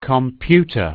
Computer2.wav